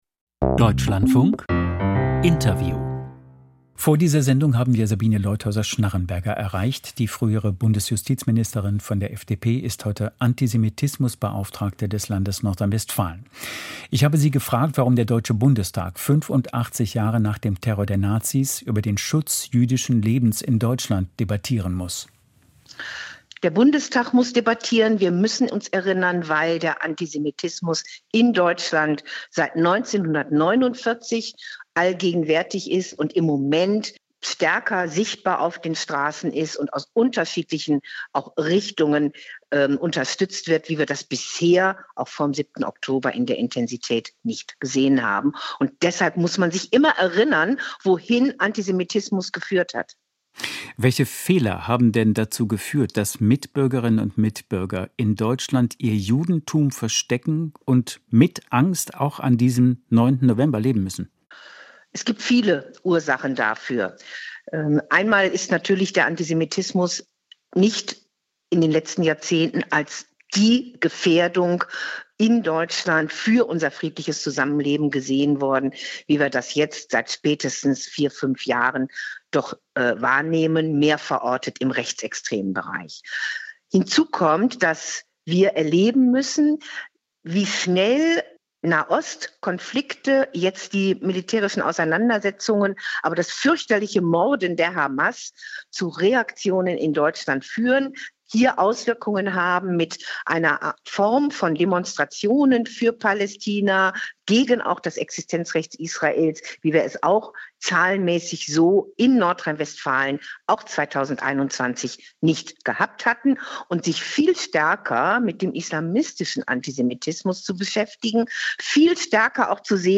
Antisemitismus - Interview mit Sabine Leutheusser-Schnarrenberger (FDP)